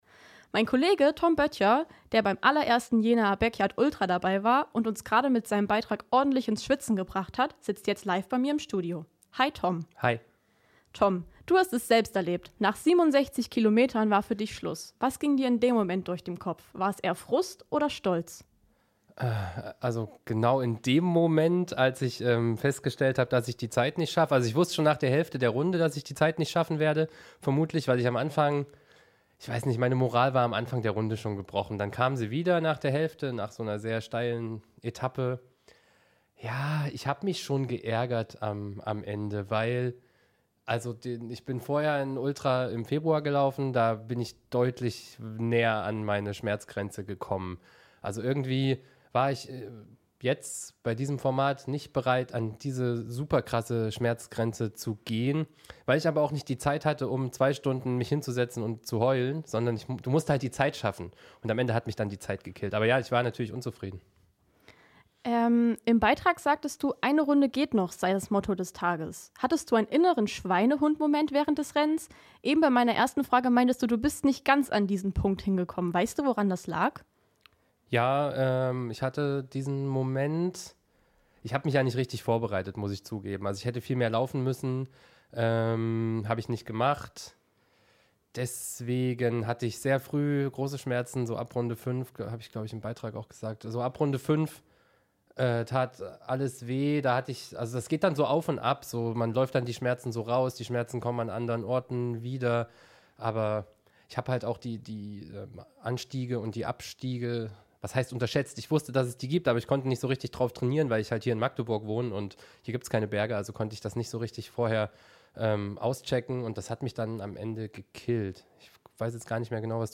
Kollegengespräch – Backyard Ultra-Marathon – h²radio